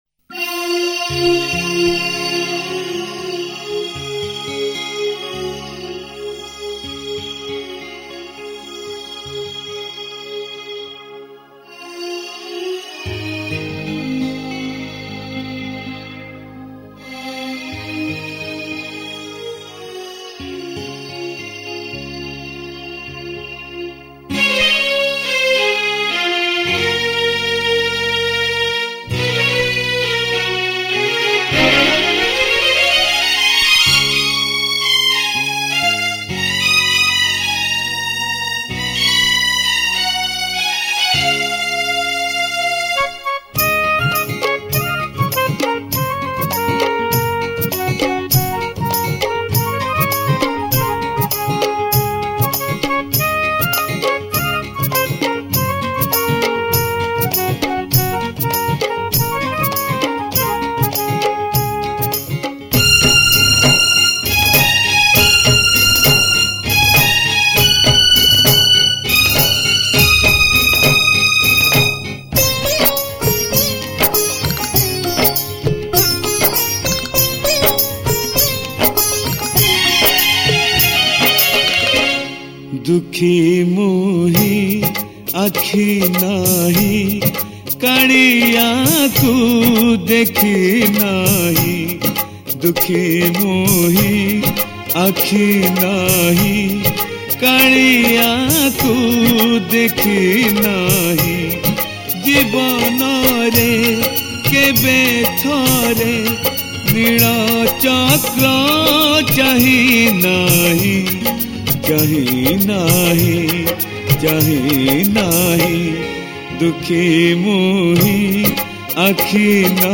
Odia Bhajan Hits